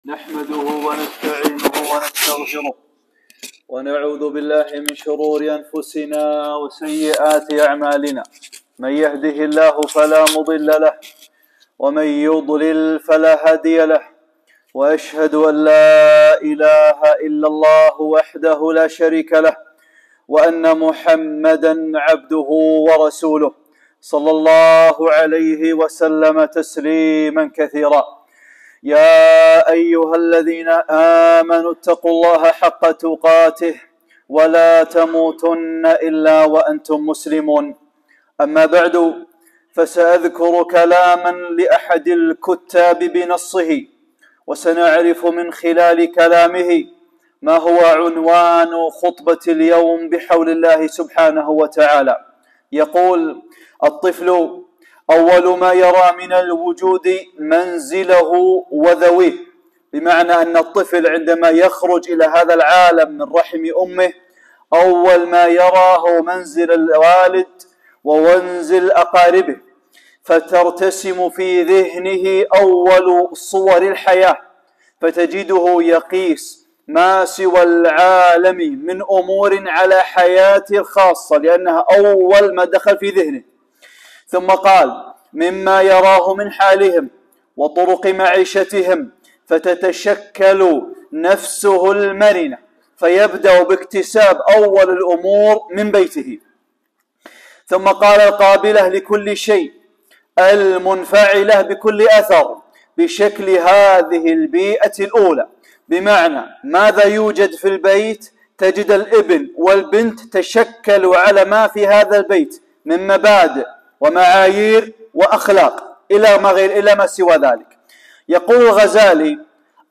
(1) خطبة - أيها الآباء أيتها الأمهات واجبكم عظيم - نقاط مهمة للآباء والأمهات